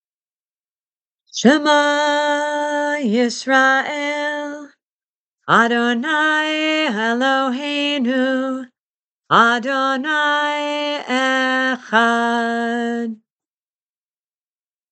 These audio files are available for students and others to hear and practice prayers and songs often included at Tikkun v’Or Shabbat services.